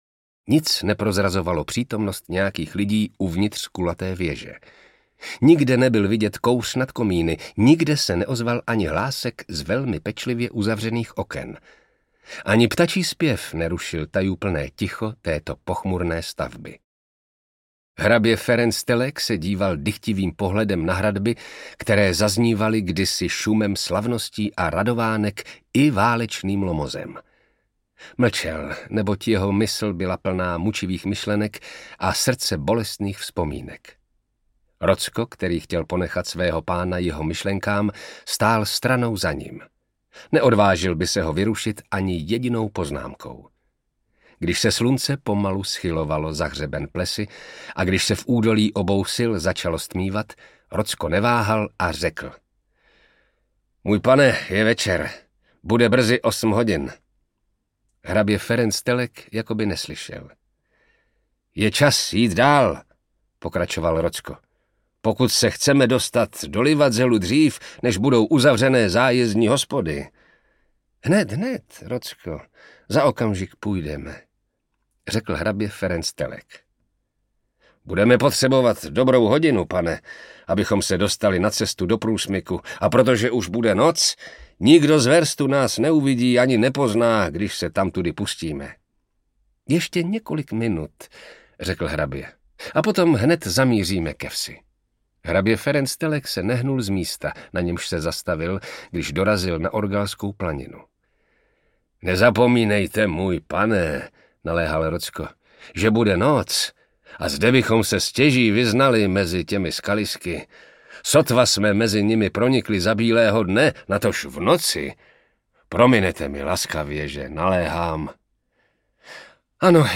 Tajemný hrad v Karpatech audiokniha
Ukázka z knihy
Vyrobilo studio Soundguru.